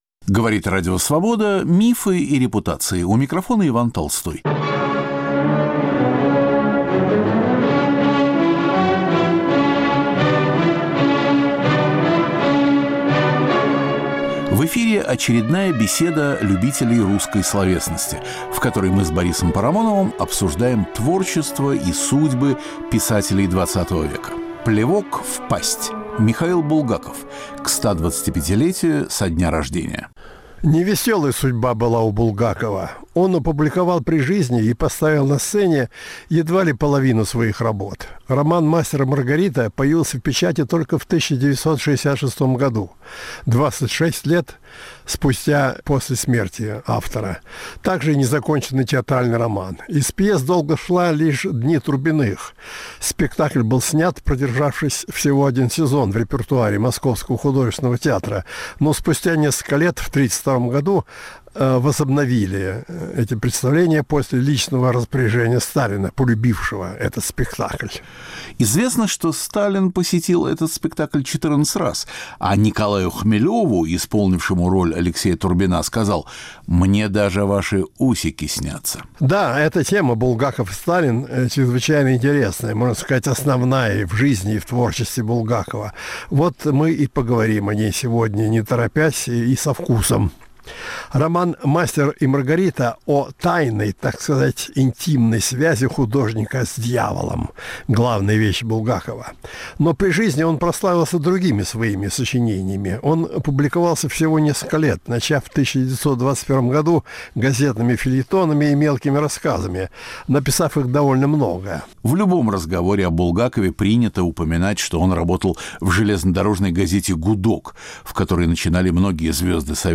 Борис Парамонов в беседе с Иваном Толстым обсуждают судьбу Михаила Булгакова. К 125-летию со дня рождения писателя.